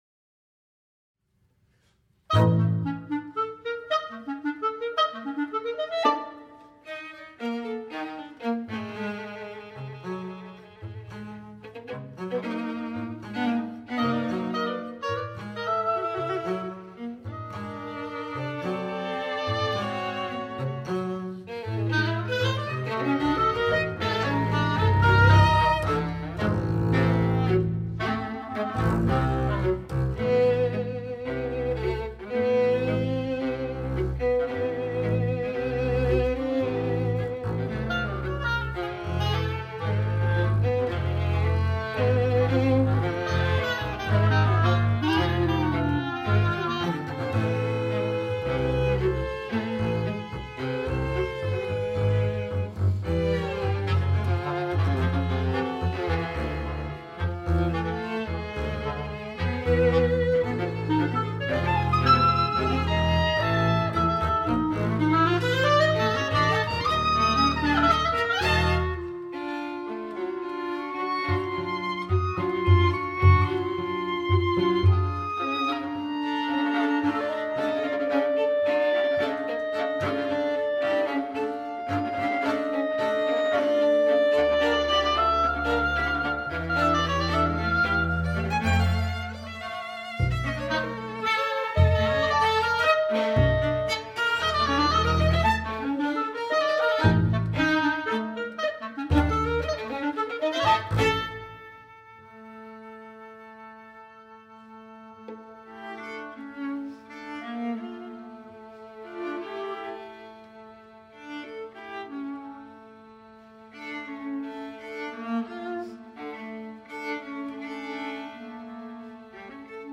oboe
clarinet
violin
viola
bass